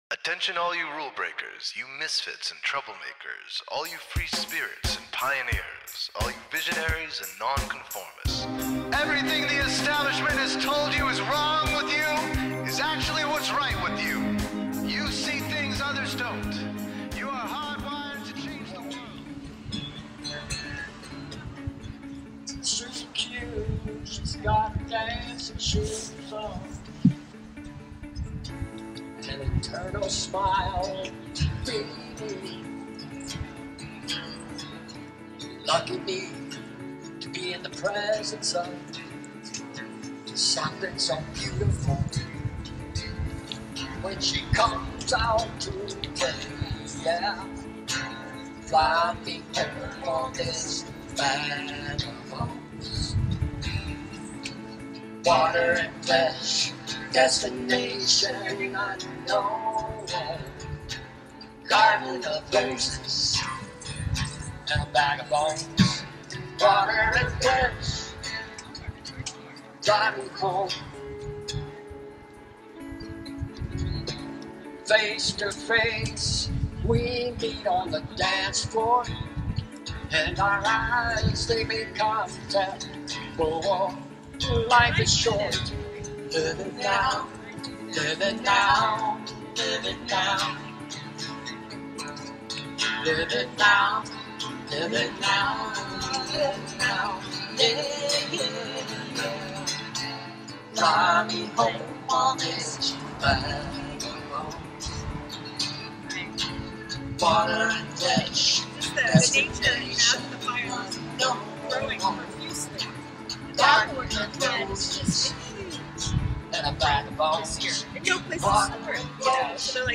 Paradise Revival Festival Interviews